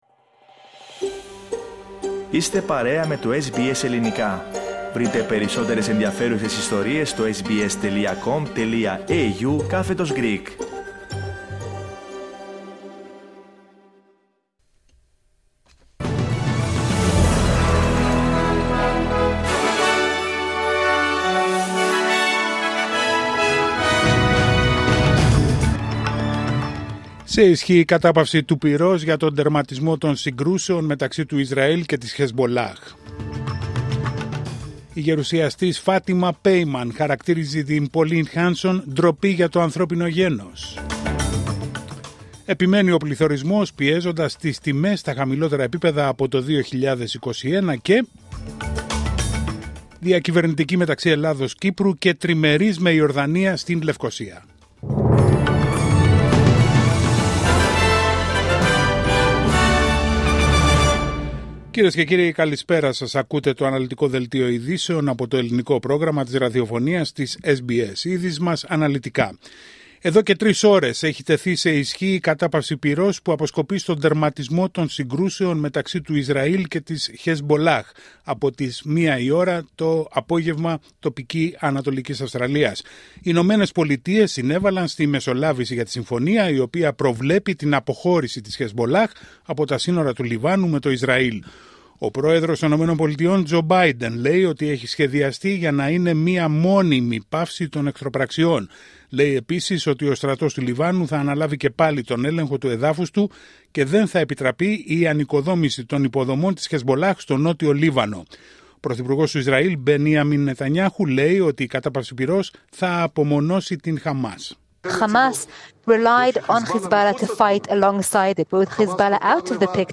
Δελτίο ειδήσεων Τετάρτη 27 Νοεμβρίου 2024